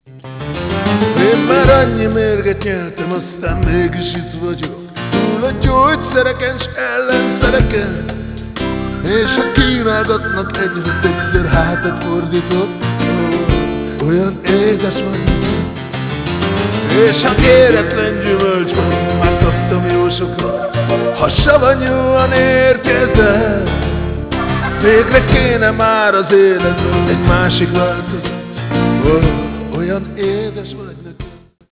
zongora, Hammond orgona, clavinet, harmonika, ütőhangszerek
gitárszóló
altszaxofon, szoprán szaxofon
dobok
trombita
harsona
ének